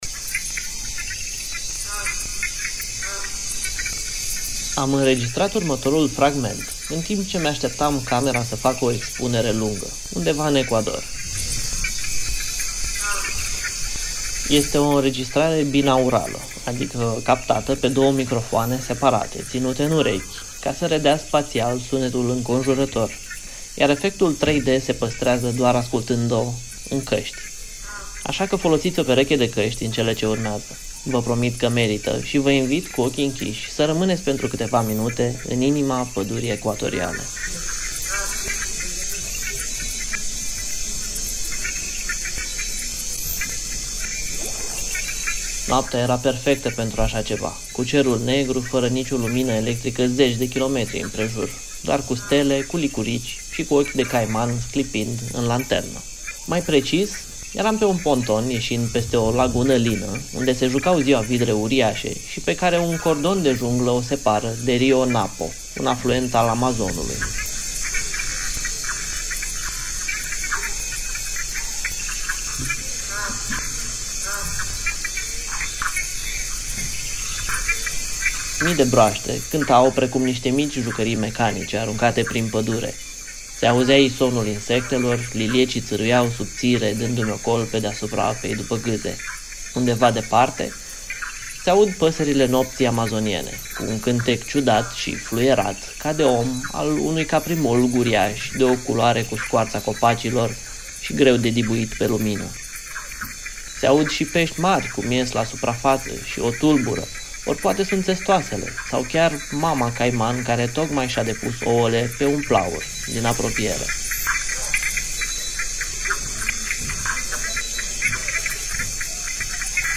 Bucuria de a fi | Sunete binaurale
Adică separat cu fiecare ureche, în căști. În acest fel, ne putem teleporta cu ajutorul auzului în mijlocul pădurii ecuatoriale, să ascultăm miile de zgomote ale animalelor de pe acele tărâmuri îndepărtate:
30-oct-BDF-Sunete-binaurale.mp3